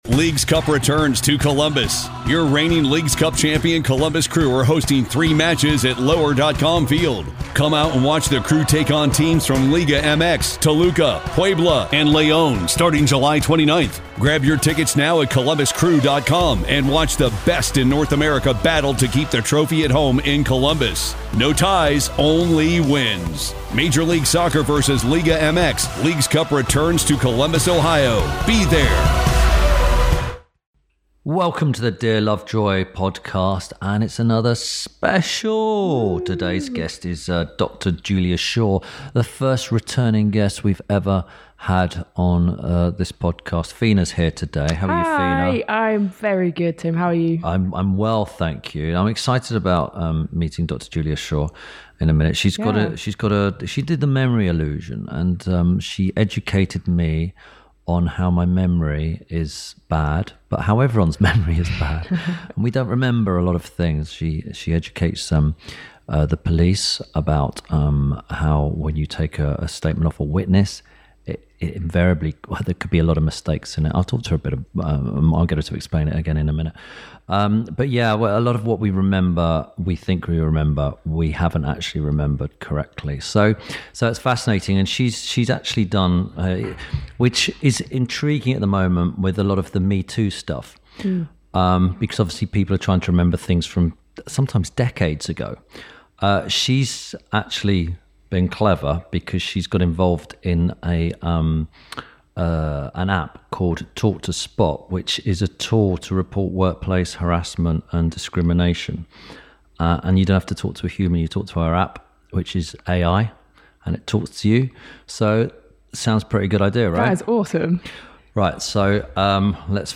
This week Tim Lovejoy talks to memory scientist and author Dr. Julia Shaw. Whilst chatting about Dr. Julia’s new app, they discuss how we perceive reality and also workplace harassment and how to spot it and report it.